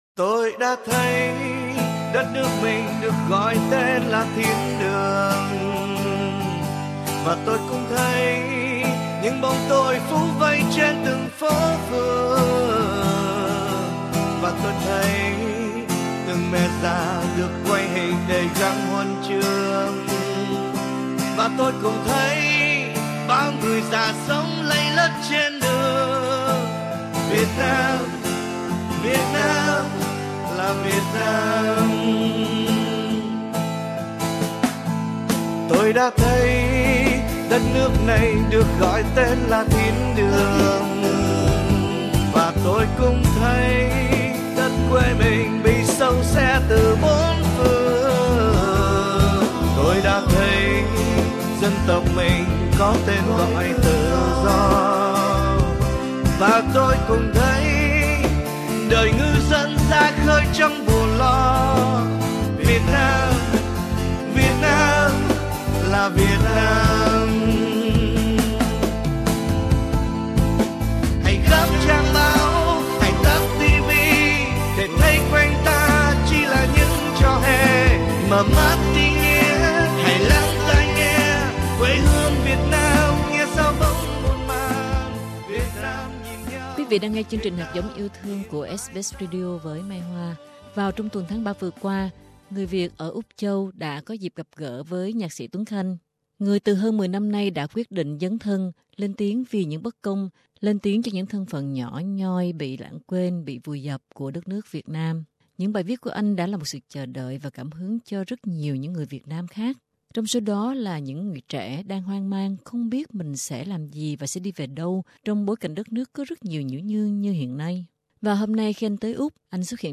Trong một đêm mưa đầu thu, trăng quá rằm vẫn dát lên những táng cây đẫm nước, giữa tiếng dế vô ưu rỉ rả sau cơn mưa vừa tạnh tại một ngôi vườn ở Sydney